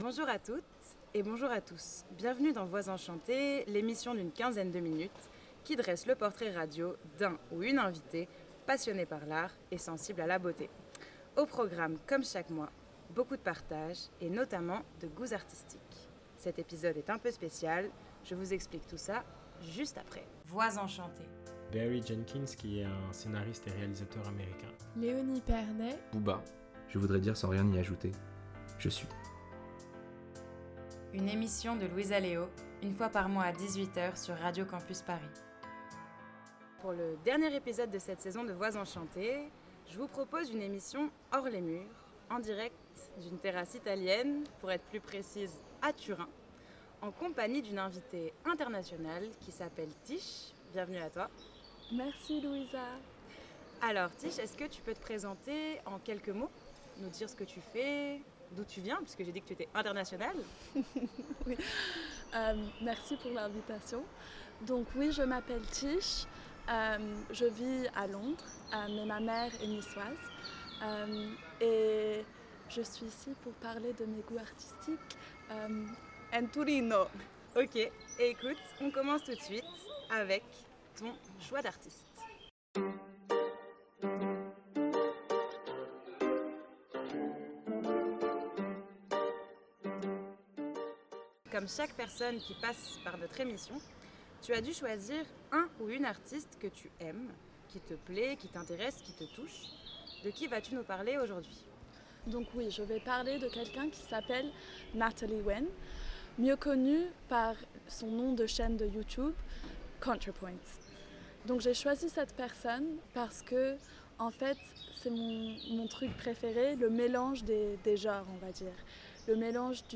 Nous nous sommes retrouvées à Turin, en Italie, pour un épisode hors les murs et les frontières !
Type Entretien